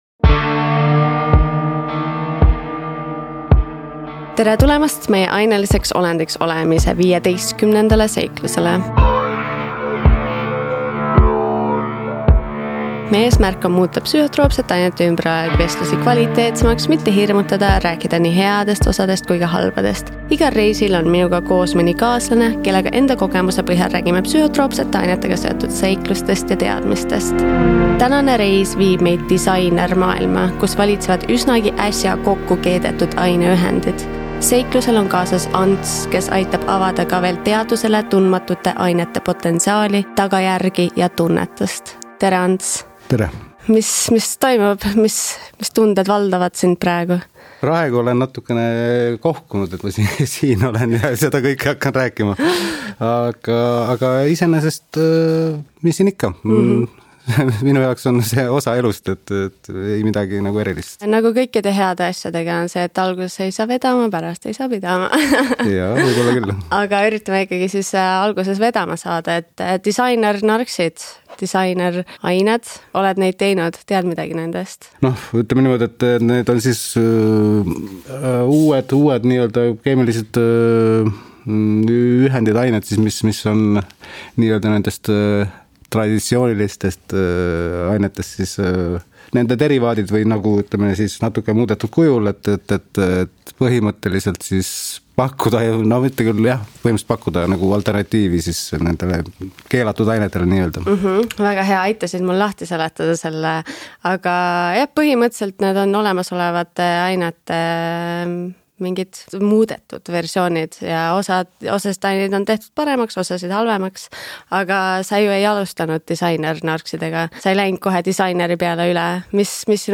Seekordne külaline tuleb saatesse geebeka ehk GHB laksu all, mis toob kaasa mitmeid ootamatuid olukordi.